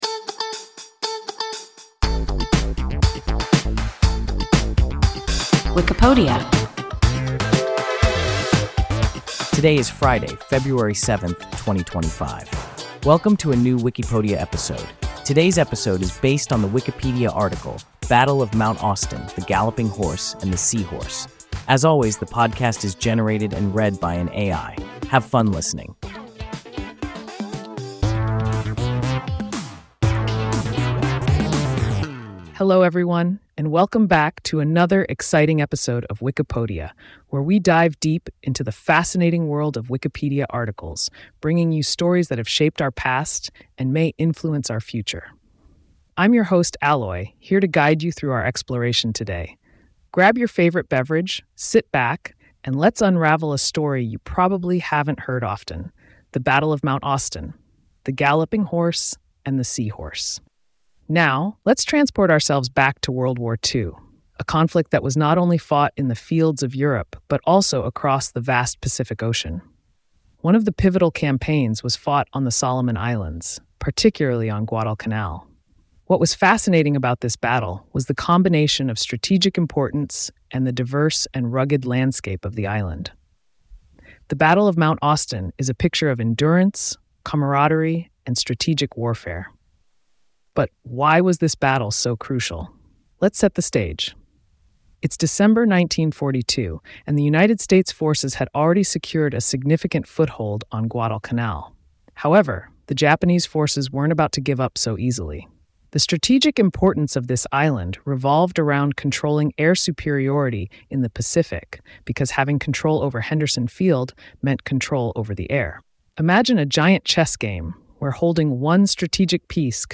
Battle of Mount Austen, the Galloping Horse, and the Sea Horse – WIKIPODIA – ein KI Podcast